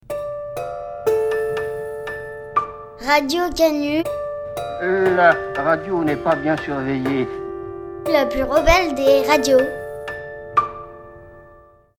Remixes et autres espèces de petits sons courts
JINGLES